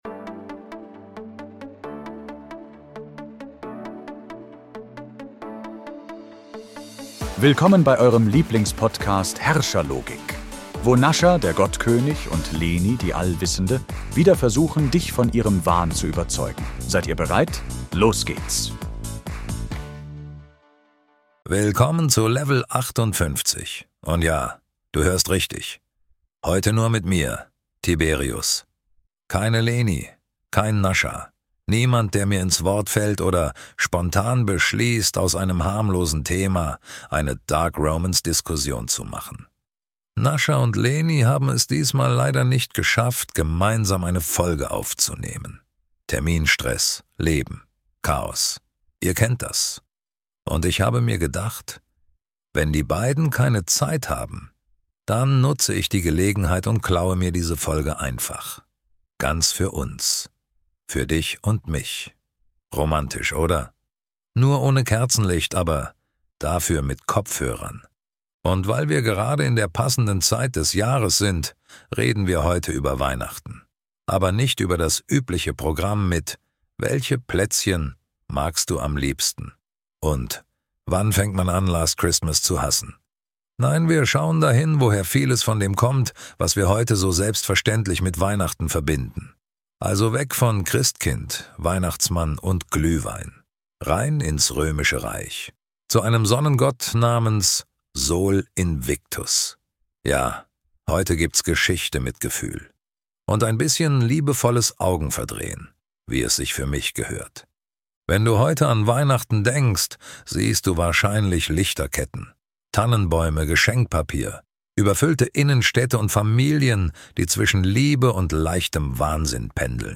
ganz allein am Mikro